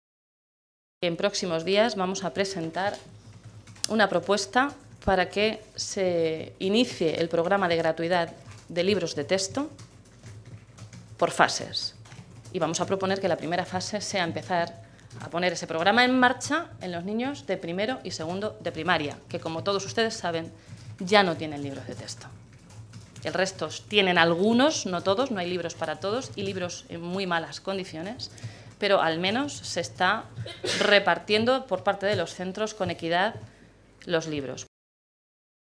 La diputada regional socialista hacía este anuncio hoy, en una comparecencia ante los medios de comunicación en Toledo, en la que recordaba que “estamos en una región en la que hay 90.000 familias con todos sus miembros en paro, en la que la tasa de paro está por encima de la media nacional y en la que la cobertura por desempleo es menor que la que hay en el resto del país”.
Cortes de audio de la rueda de prensa